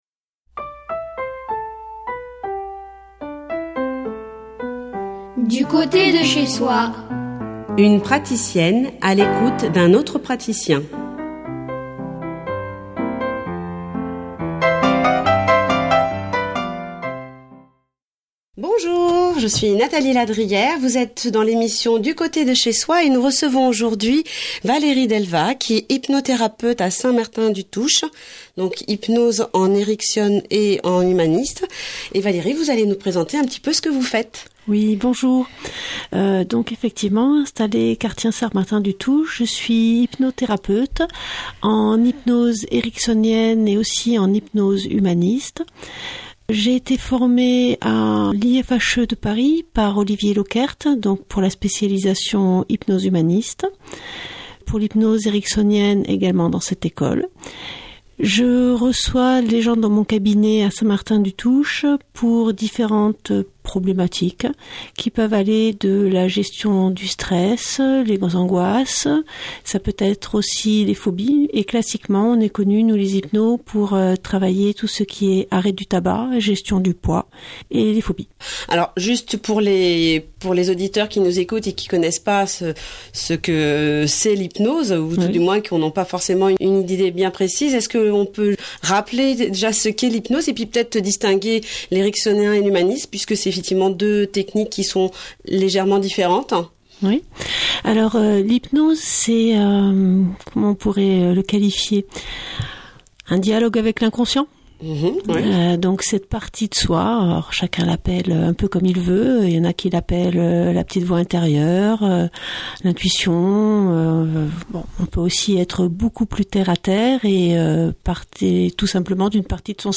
Interview radio dans l'émission "Du côté de chez Soi", diffusée le 22 février 2019.